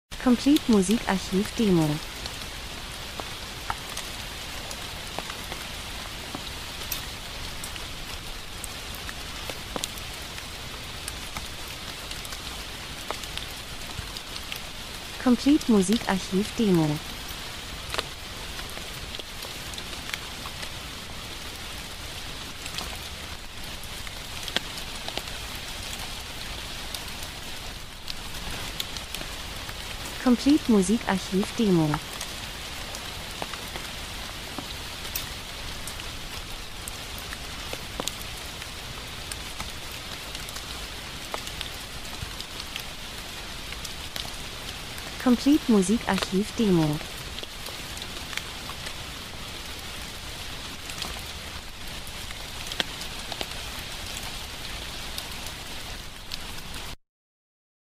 Herbst -Geräusche Soundeffekt Regen prasselt 00:57